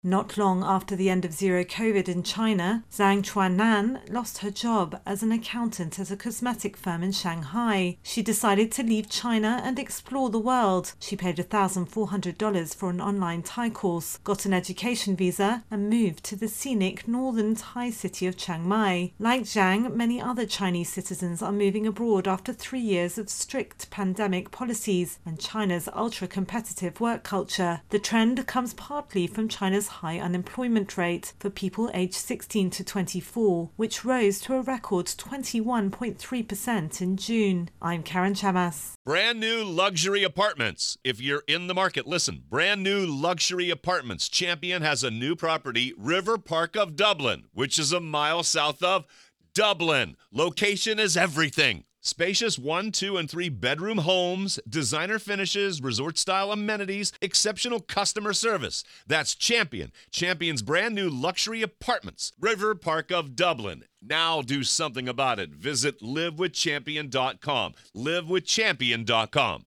reports on Chinese nomads.